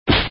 11_light_punch.wav.mp3